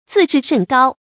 自视甚高 zì shì shèn gāo 成语解释 把自己看得很高（多指身分，学识等）。